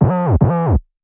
weird 808 slide.wav